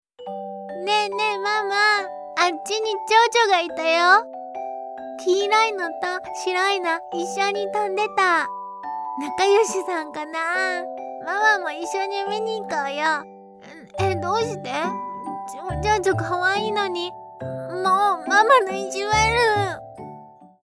Character Samples
Preschool Girl (3-6 yo)